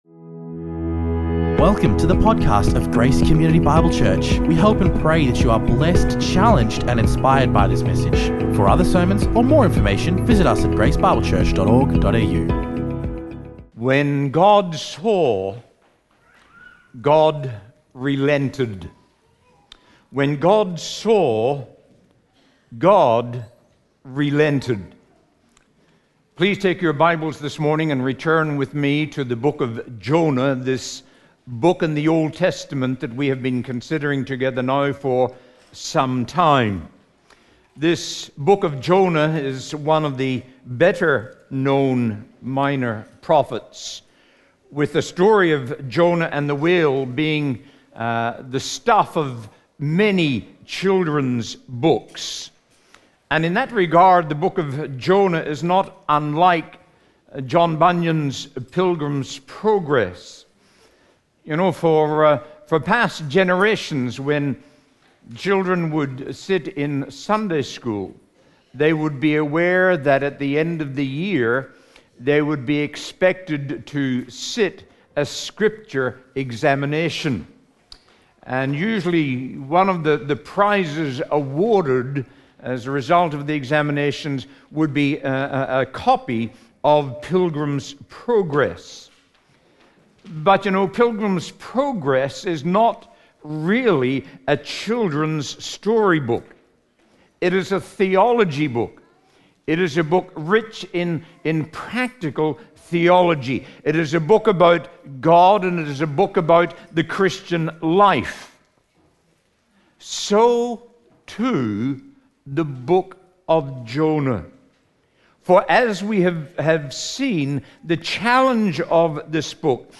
Guest preacher
recorded live at Grace Community Bible Church